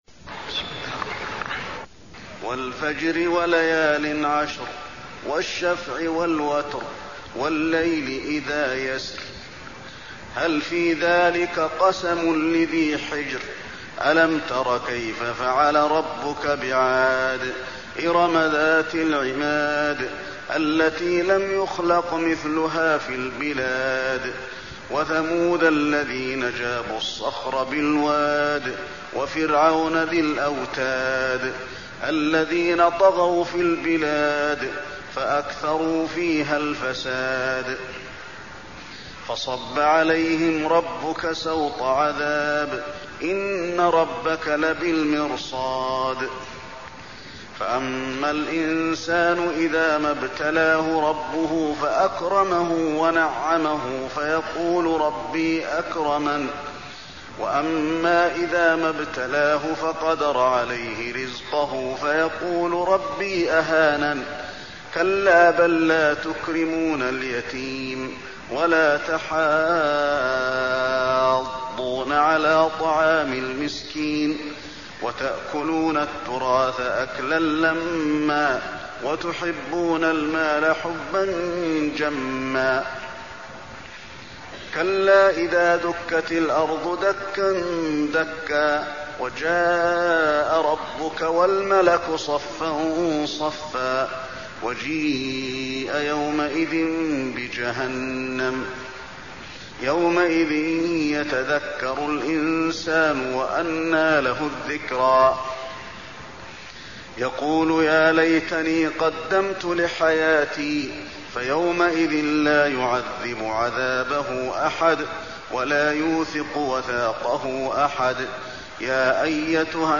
المكان: المسجد النبوي الفجر